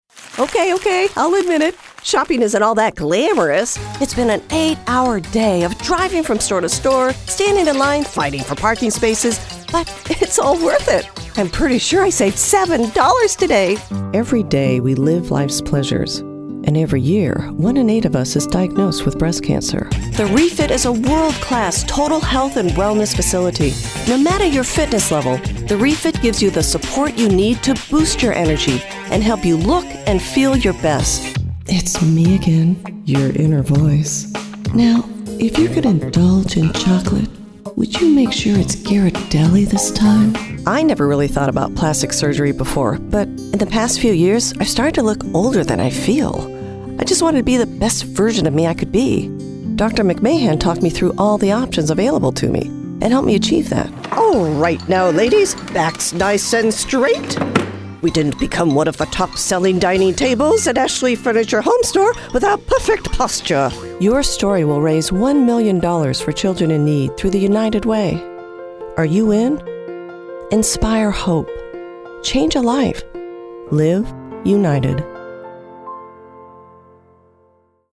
Warm, professional, corporate, articulate, friendly, animated, fun, humorous, wry... and much more
Sprechprobe: Werbung (Muttersprache):
My home studio is fully equipped to provide you with clean, edited and finished audio files.